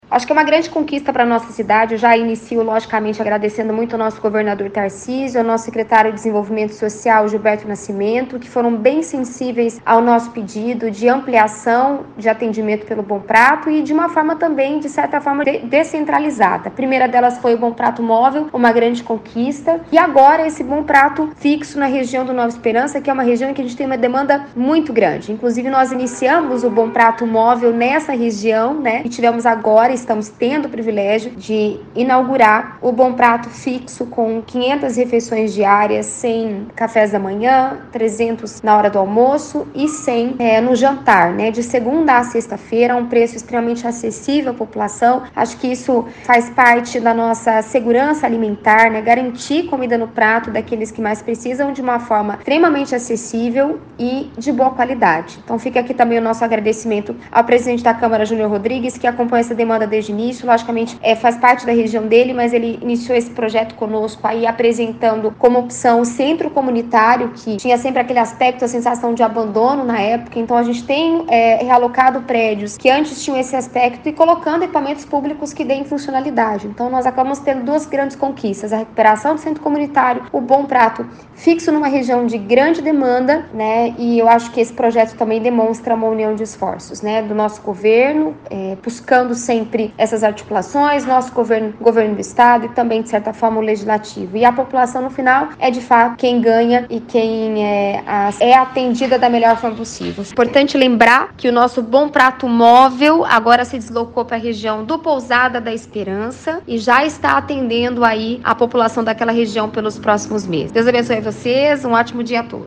A Prefeita Suellen Rosim esteve na inauguração e comentou a importância do espaço.